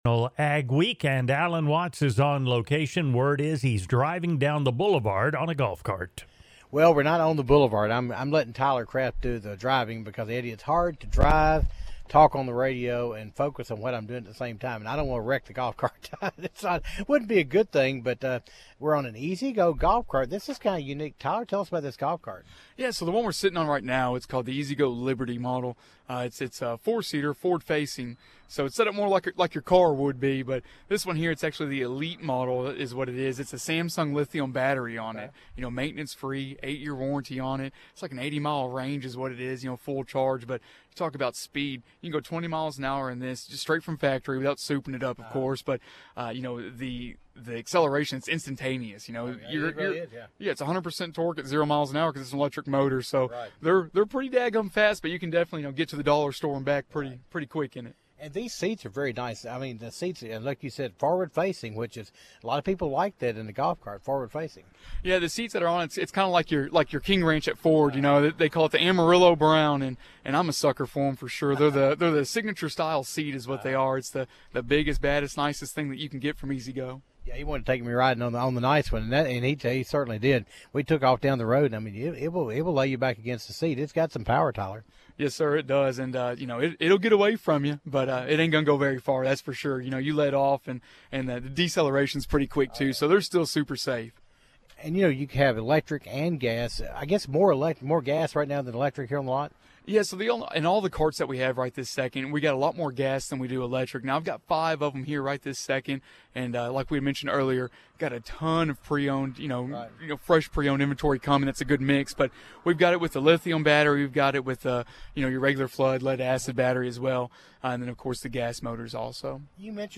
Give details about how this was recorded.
take to the road on one of 1st Choice Lawn & Garden’s golf carts.